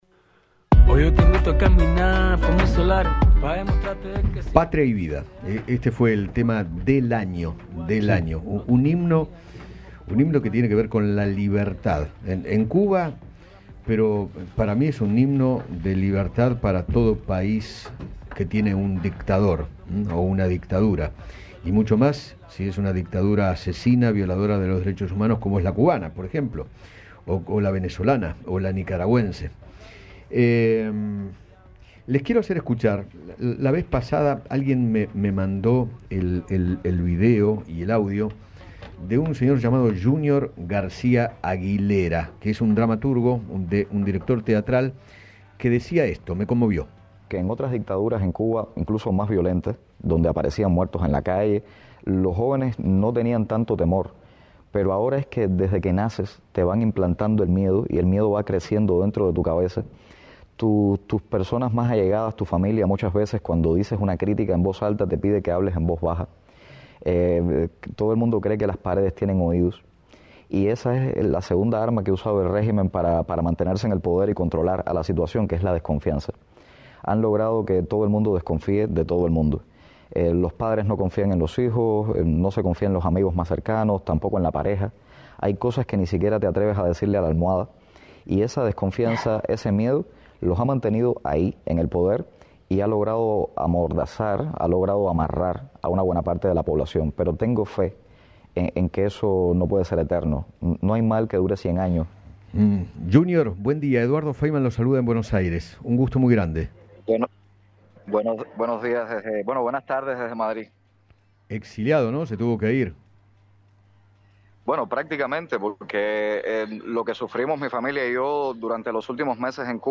Yunior García Aguilera, dramaturgo y activista cubano, habló con Eduardo Feinmann sobre la persecución que sufrió junto a su familia por parte del régimen de Miguel Díaz-Canel.